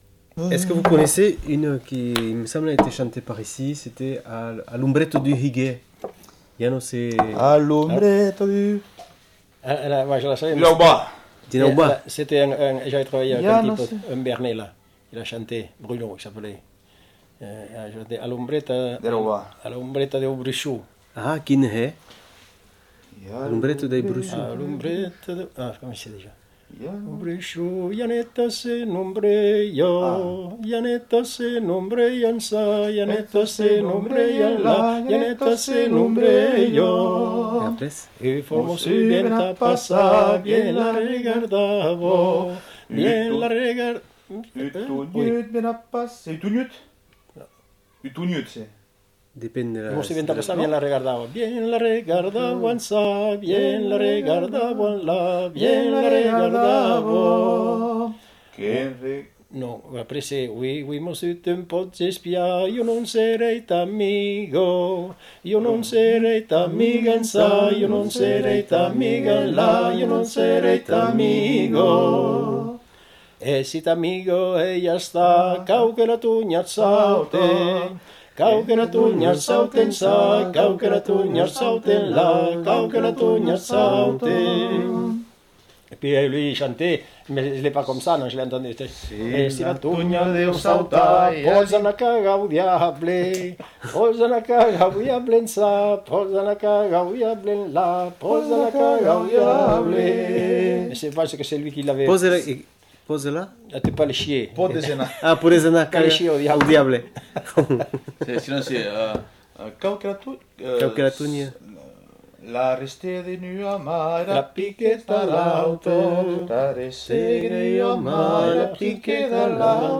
Aire culturelle : Bigorre
Lieu : Villelongue
Genre : chant
Effectif : 2
Type de voix : voix d'homme
Production du son : chanté